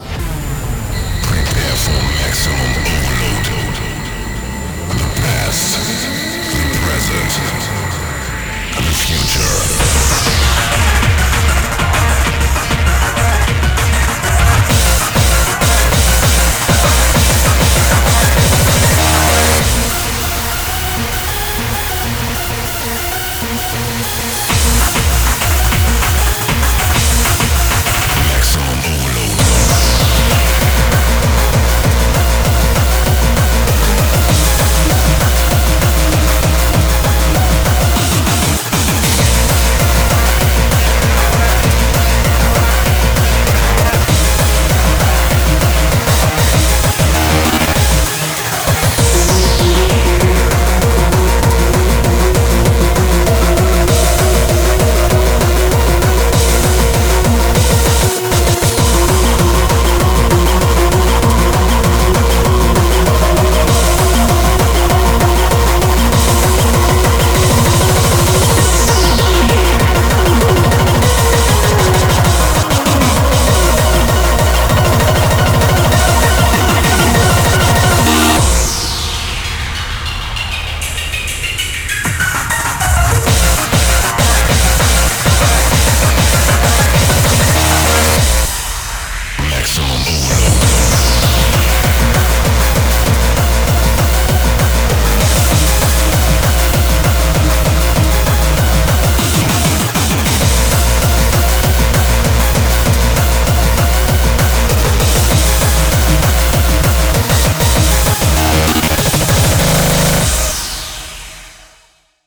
BPM49-196
MP3 QualityMusic Cut